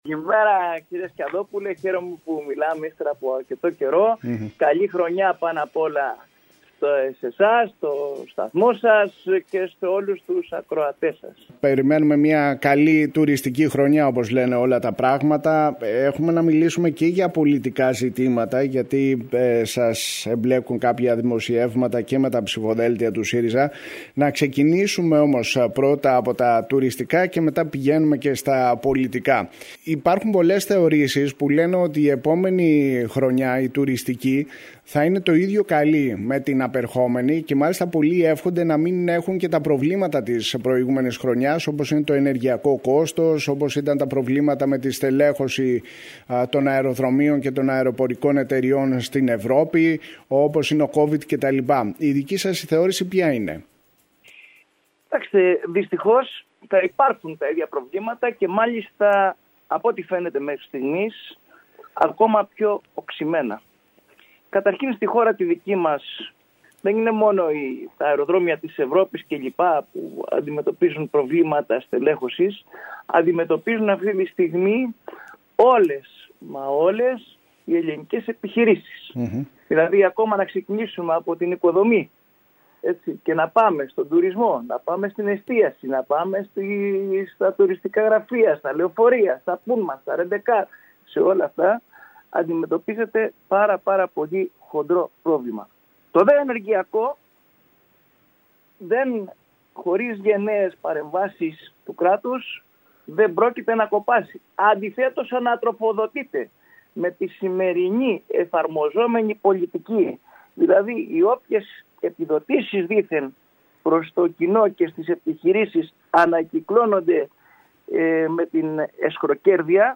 Συνέντευξη του τ. αντιπεριφερειάρχη Σπύρου Γαλιατσάτο στην ΕΡΤ Κέρκυρας.